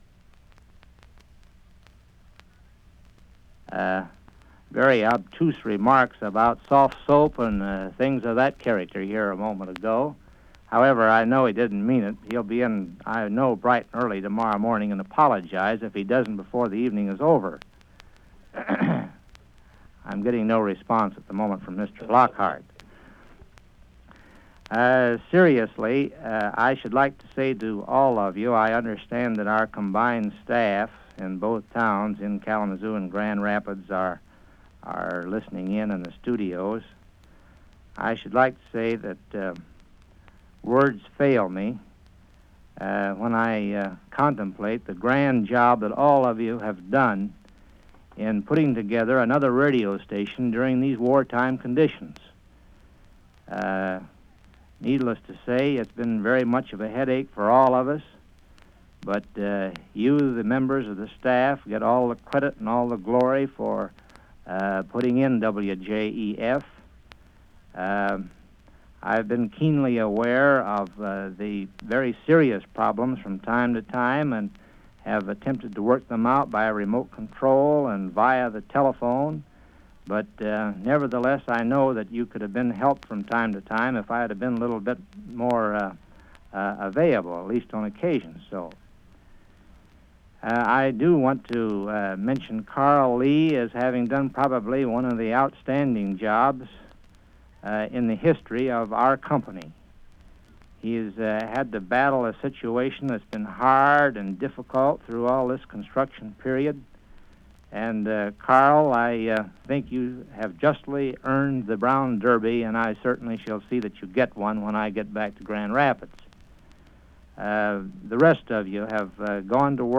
John E. Fetzer addresses the staff of radio stations WJEF and WKZO